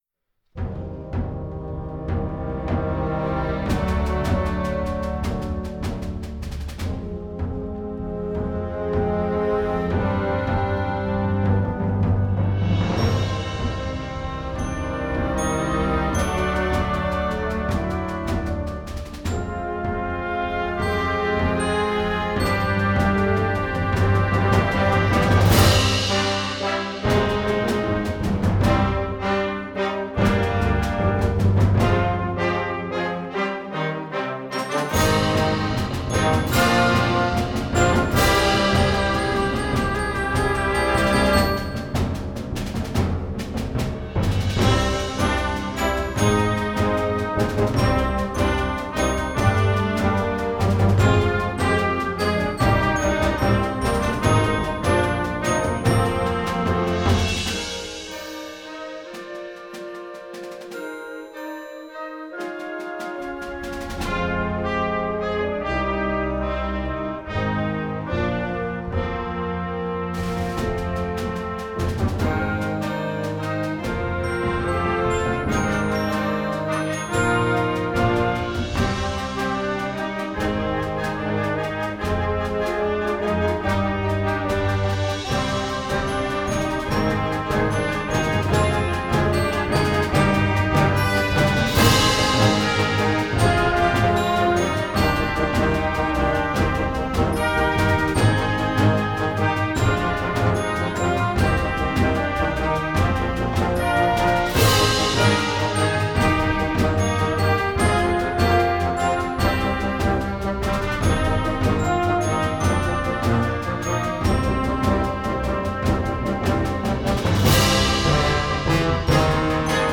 Instrumental Concert Band Concert/Contest
is a musical soundtrack that imagines a fantasy sword fight.
Concert Band